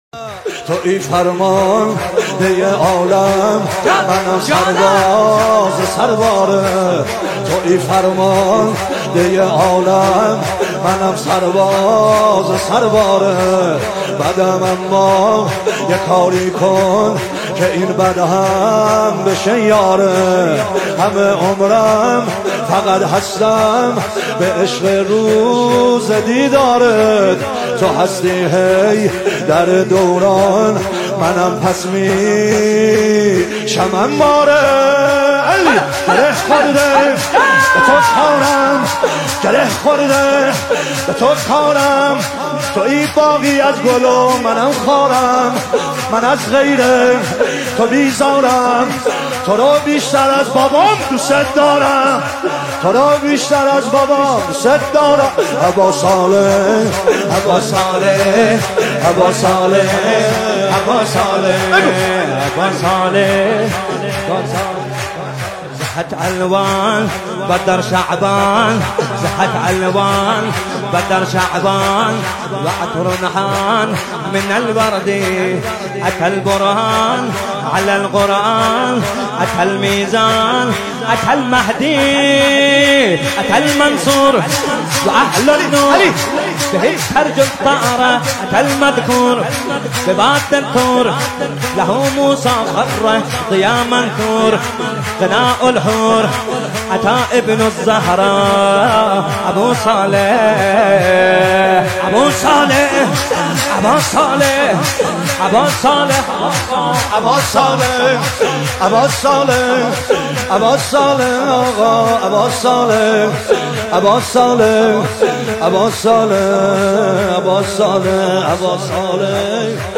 دانلود مولودی جدید
مولودی زیبای و دلنشین